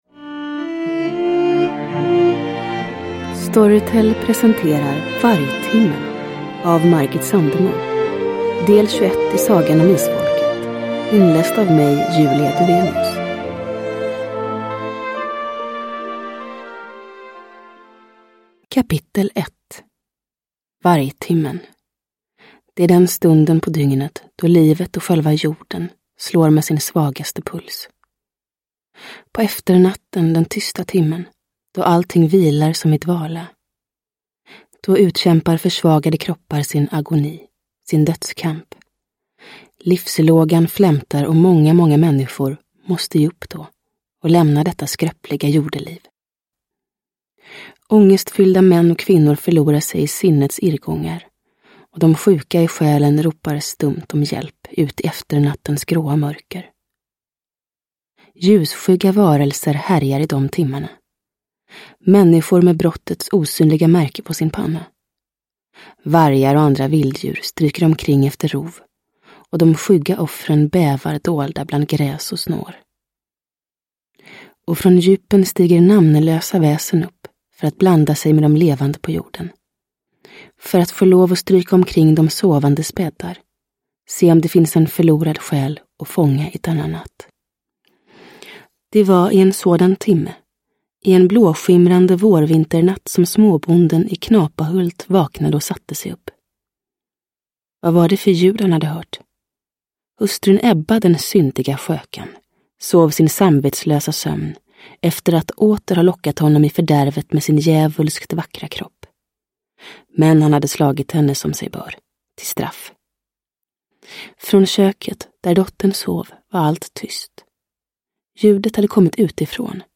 Vargtimmen – Ljudbok – Laddas ner
I suverän ny inläsning av Julia Dufvenius.
Uppläsare: Julia Dufvenius